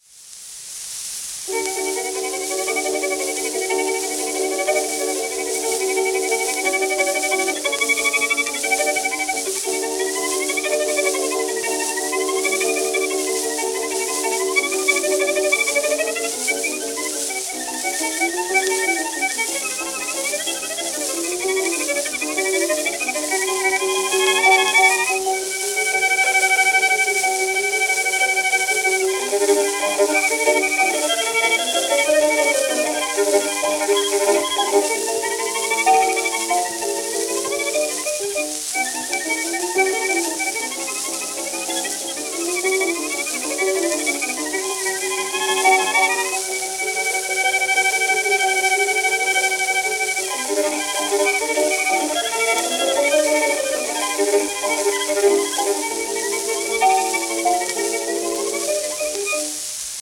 10インチ片面盤
1922年、米国録音